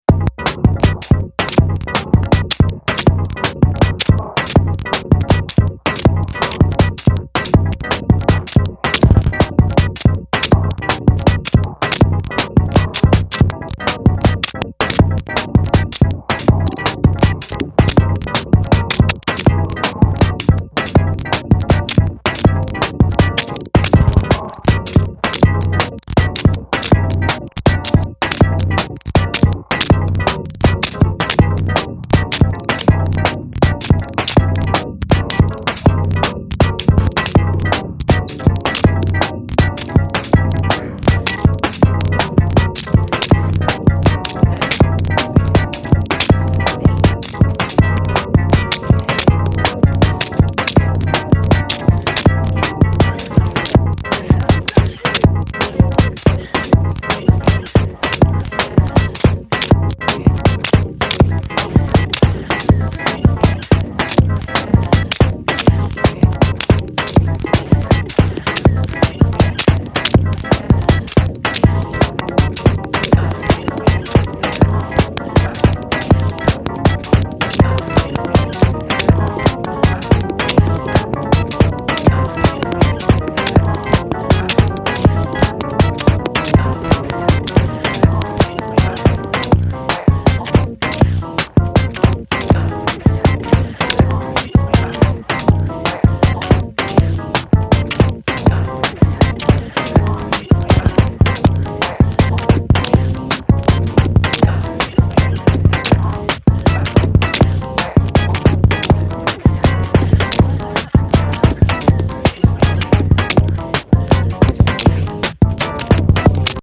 構築の逆のような八つ裂きの音がPANされてて、脳みそをつんざきますよ。